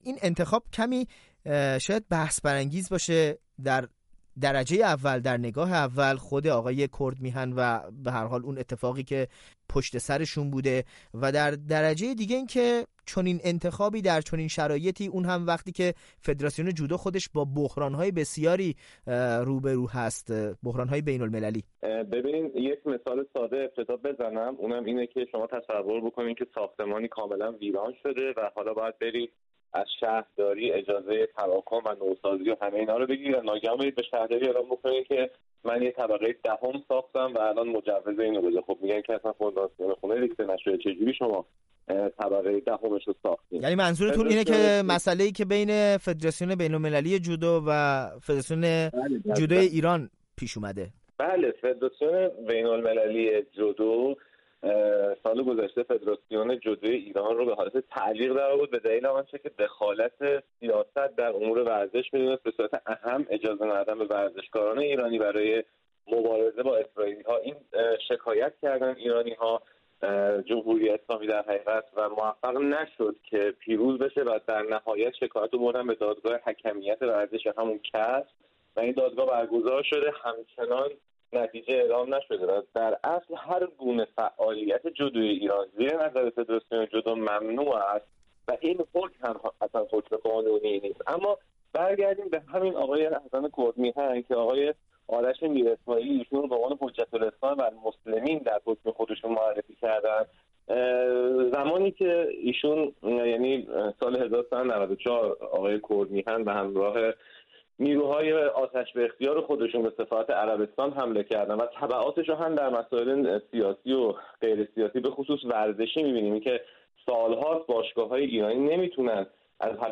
در گفت و گویی